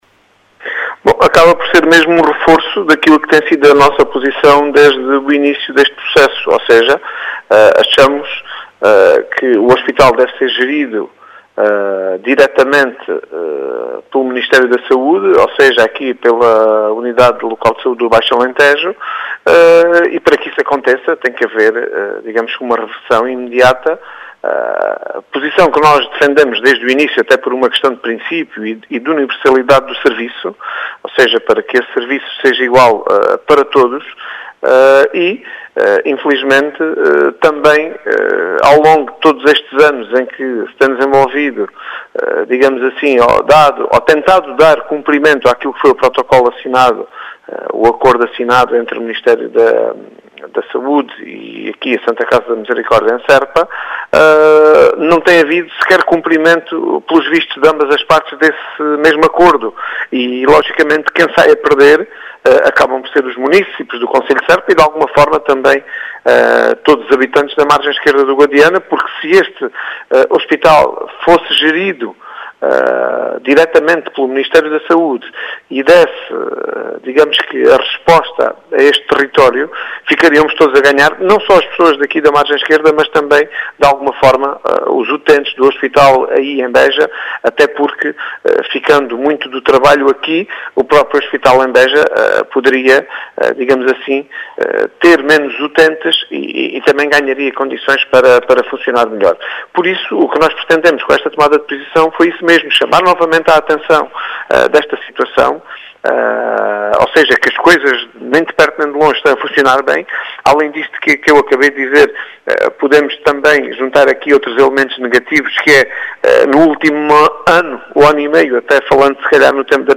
As explicações são de Tomé Pires, presidente da Câmara de Serpa, que defende a “reversão imediata” do Hospital de São Paulo, para a tutela do Ministério da Saúde, uma vez que há uma “instabilidade” no serviço de urgência do Hospital.